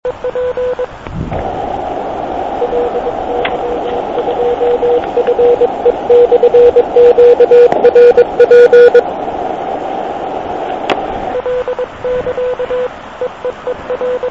Ovšem poslech v místě, kde není široko daleko žádné elektrické vedení - to je zážitek. A jak jsem některé z Vás slyšel?